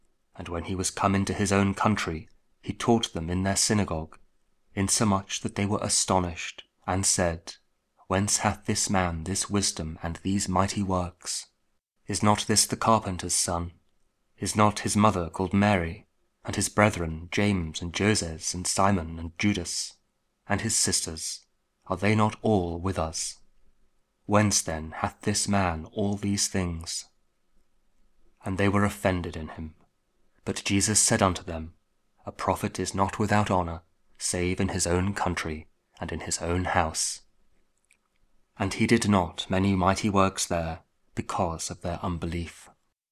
Matthew 13: 54-58 – Week 17 Ordinary Time, Friday (King James Audio Bible KJV, Spoken Word)